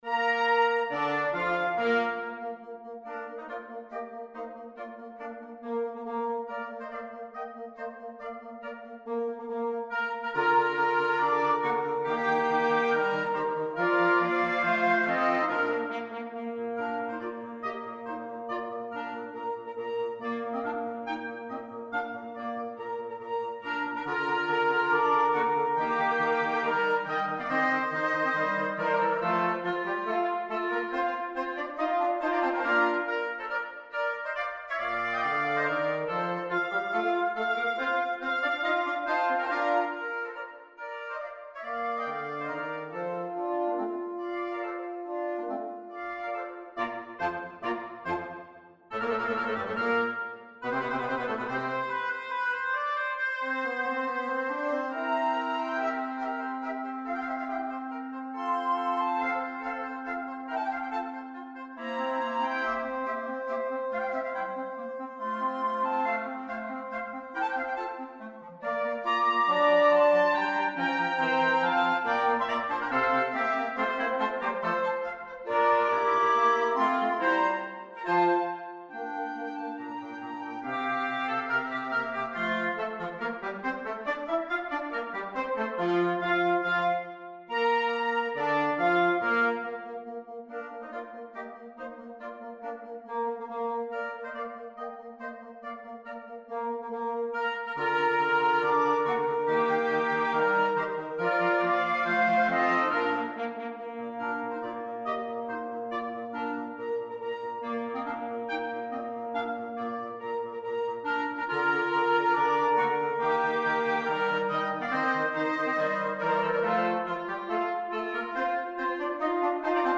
Gattung: für Bläserquintett
Besetzung: Ensemblemusik für 5 Holzbläser
bearbeitet für Flöte, Oboe, Klarinette, Horn und Fagott.